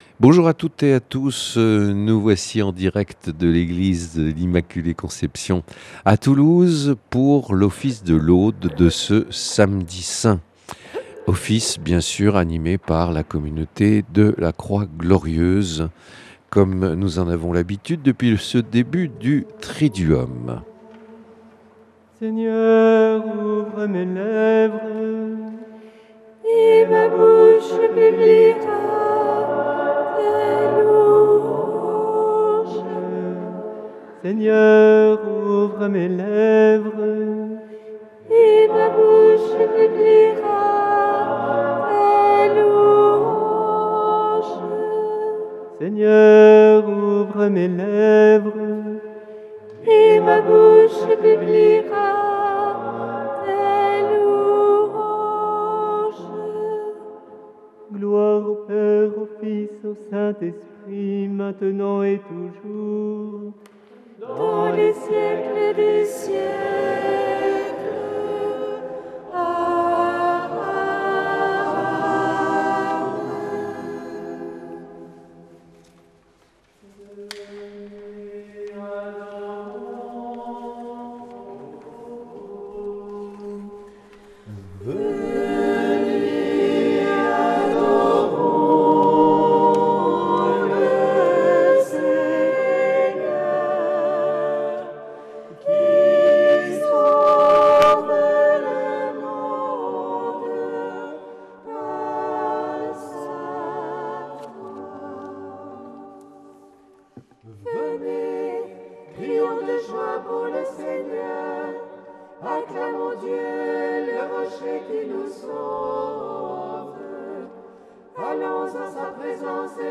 Office des laudes du Samedi Saint
En ce Samedi Saint, nous nous rassemblons pour les laudes, méditant dans le silence le mystère du tombeau vide et la promesse de la Résurrection. Dans l’attente de la lumière de Pâques, prions ensemble avec foi et espérance.